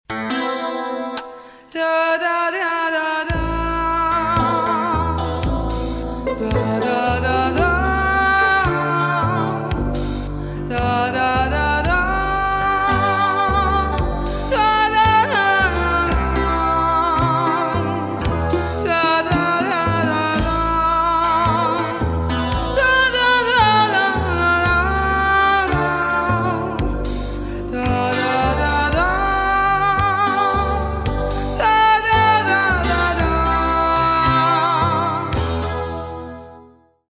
Tango Voix